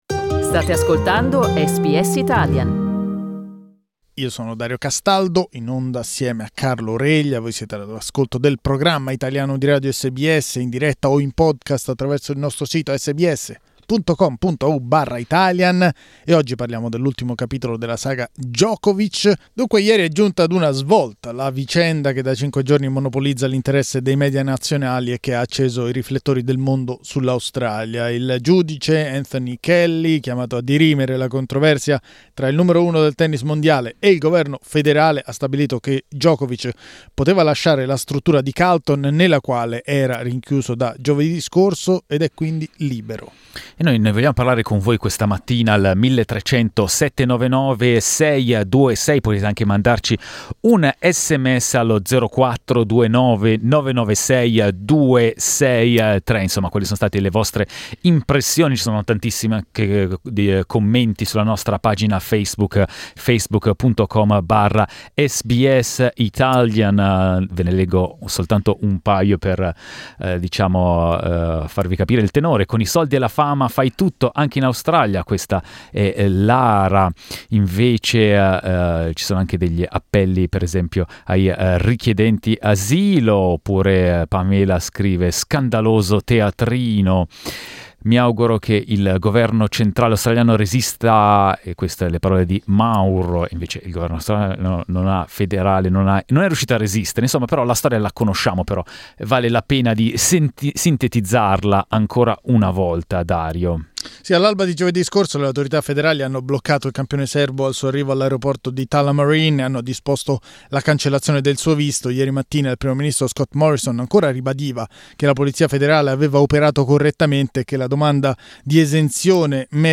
Il numero 1 del tennis mondiale è stato vittima dell'accanimento del governo o co-protagonista di una vicenda nella quale tutte le parti hanno perseguito i loro interessi? Lo abbiamo chiesto ai nostri ascoltatori.